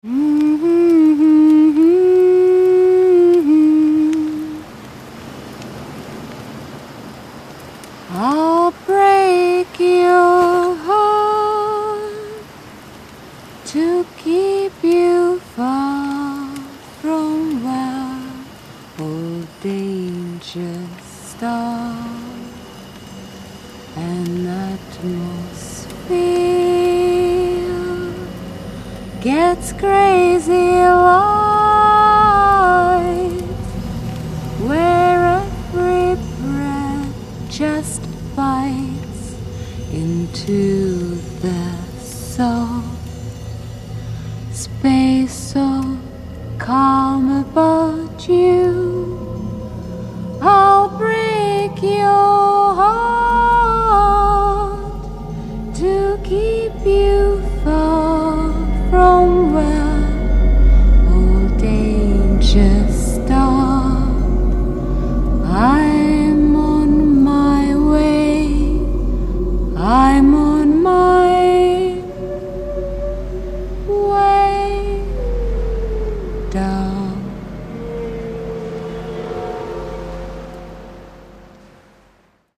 с музыкой жутковато как-то...Может быть и была такая задумка...Фото отличное!